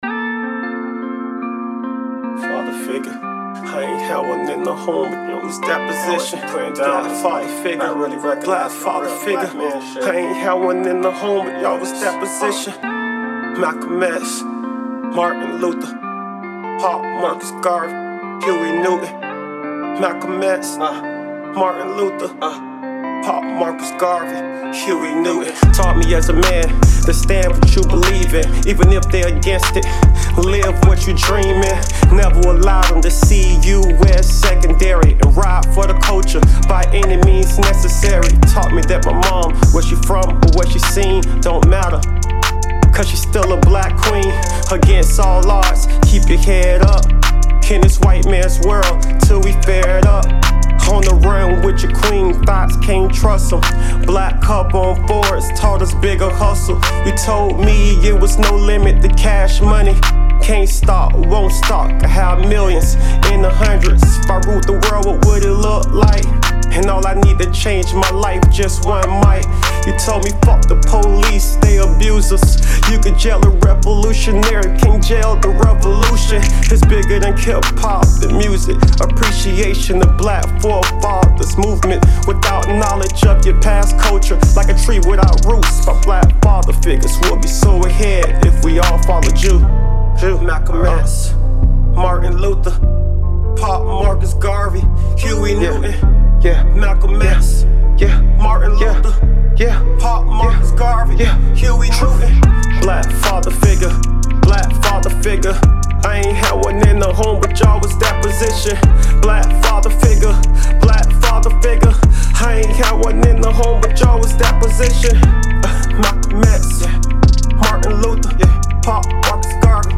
Soul